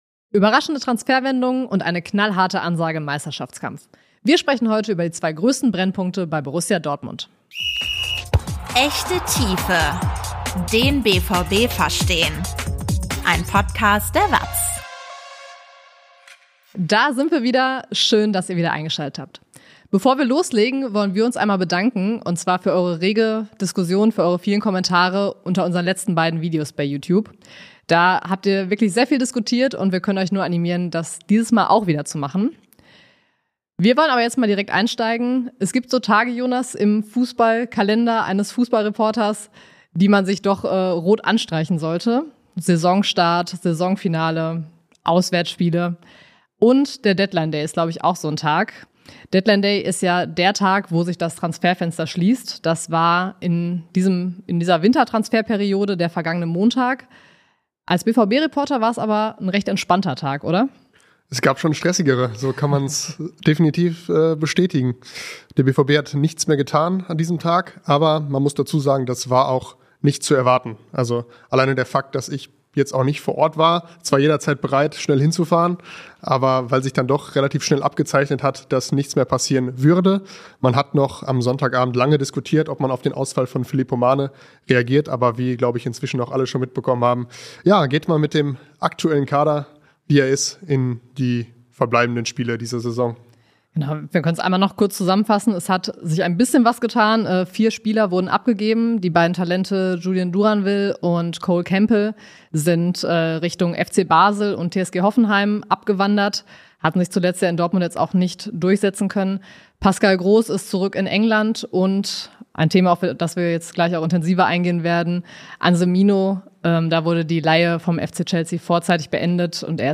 BVB-Reporter diskutieren ein Thema in der Tiefe